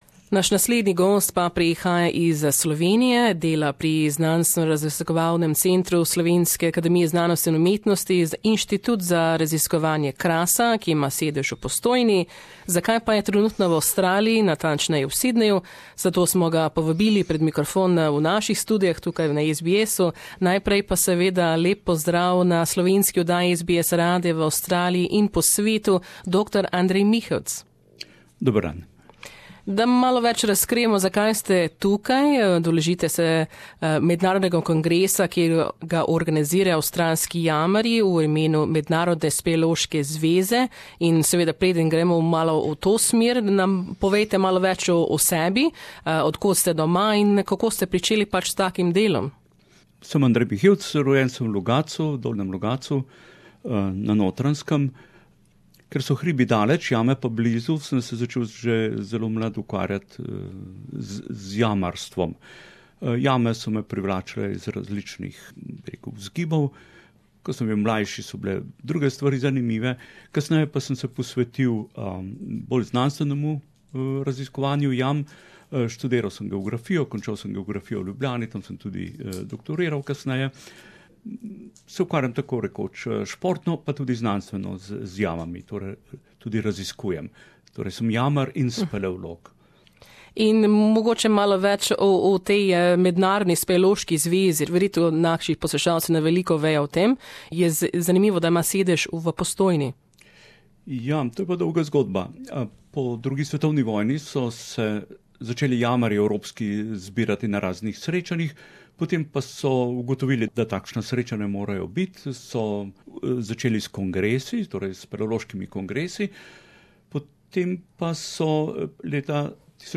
Več o njem, o jamarskem delu in kongresu v zanimivem pogovoru v studiju Radia SBS.